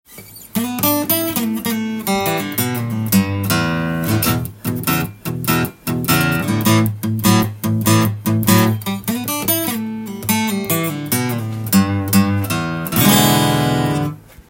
試しに弾いてみました
音の方は、カラッとした高音とミドルレンジで
高級感がある雰囲気です。
ボディーが小ぶりなのでガツガツした低音は出ませんが